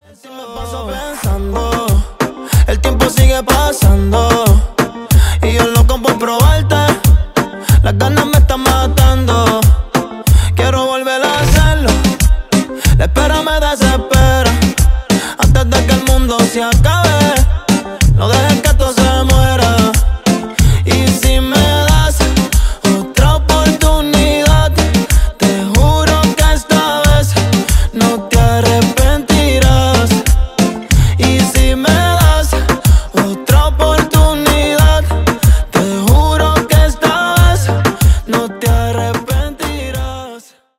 Electrónica